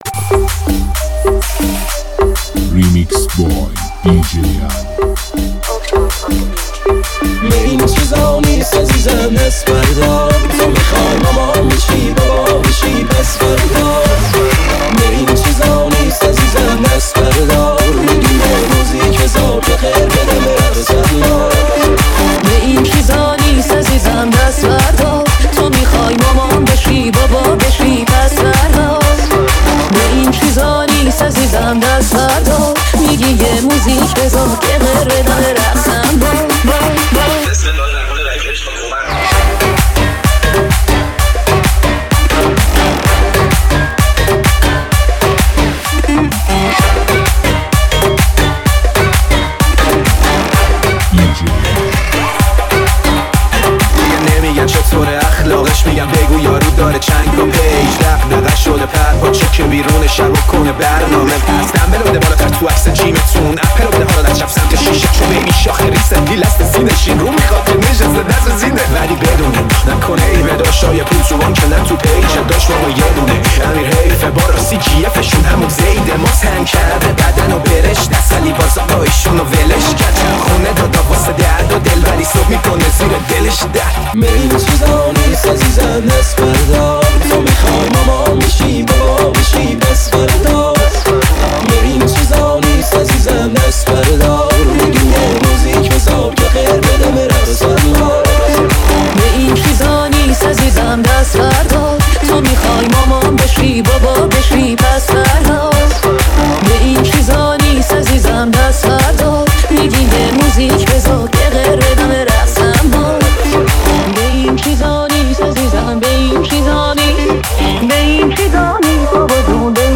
موسیقی متفاوت و پرانرژی برای لحظاتی پر از شادی و احساس.